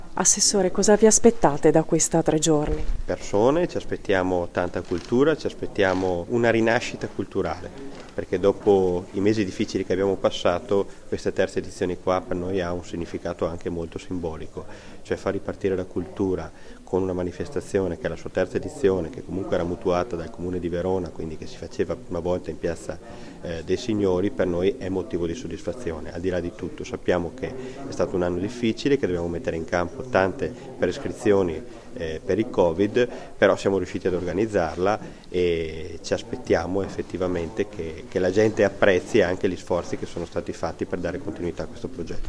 Ne hanno parlato al microfono della nostra corrispondente
l’assessore al Turismo e Manifestazioni Luca Zamperini